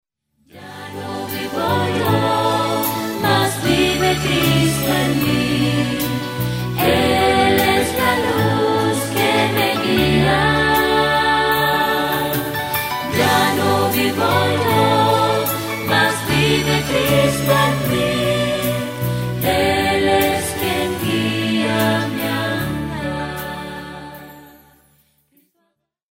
llena de adoración y reverencia